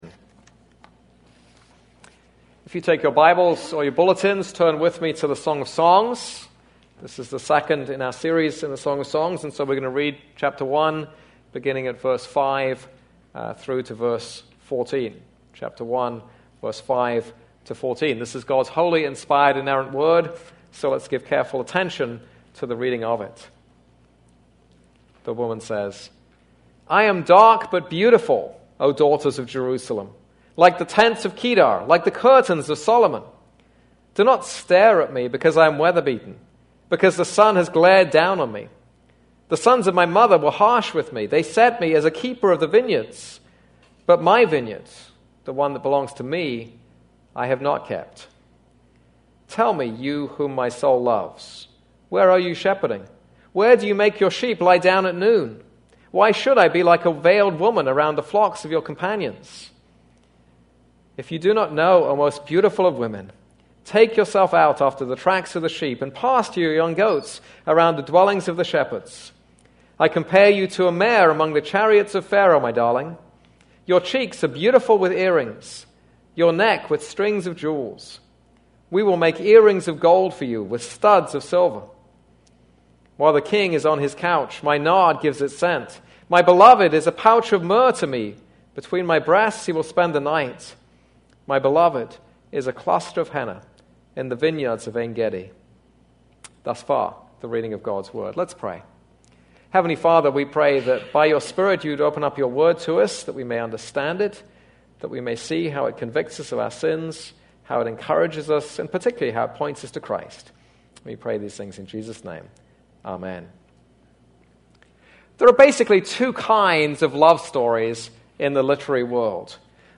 This is a sermon on Song of Songs 1:5-14.